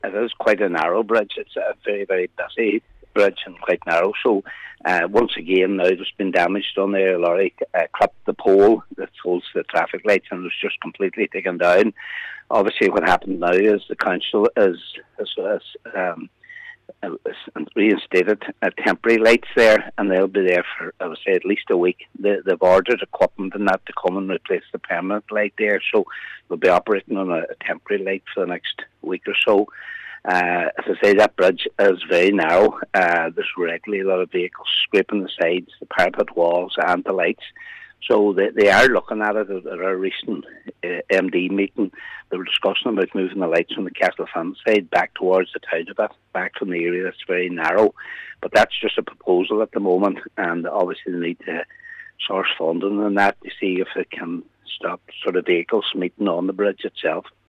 Cllr Patrick McGowan says there are proposals to move the lights closer as a safety measure, particularly as there are frequent close calls in the area……